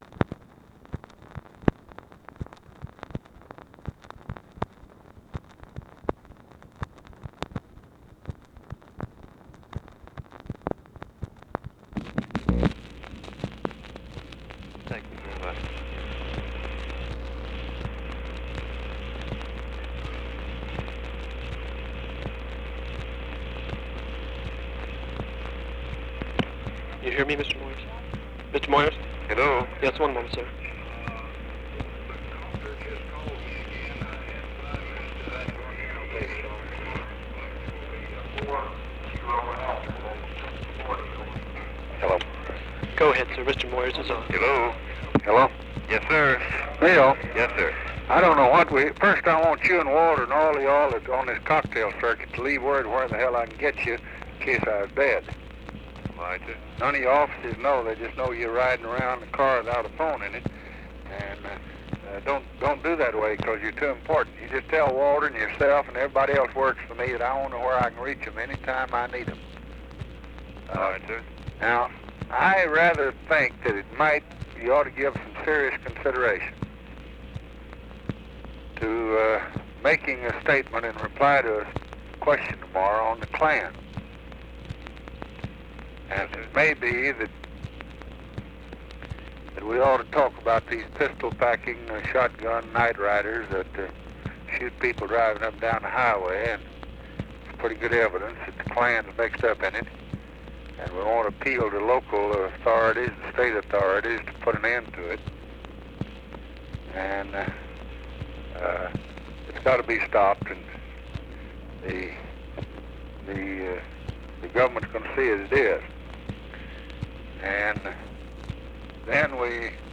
Conversation with BILL MOYERS, July 17, 1964
Secret White House Tapes